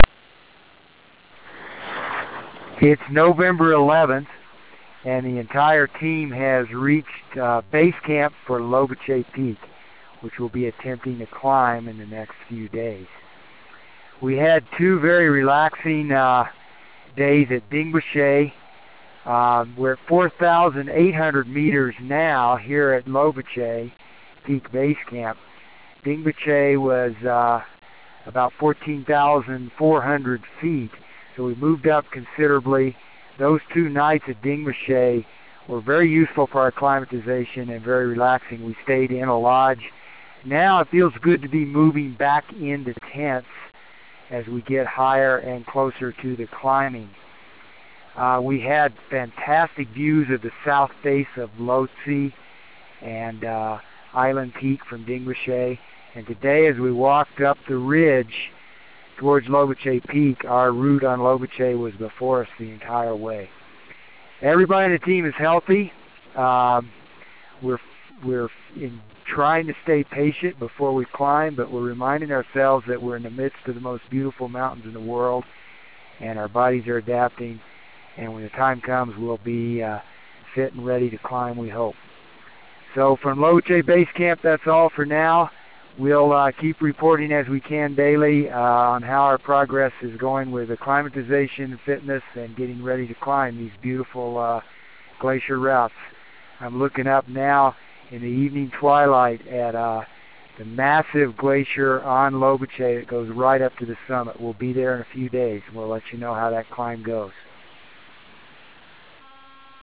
November 11 - At Base Camp for Lobuche Peak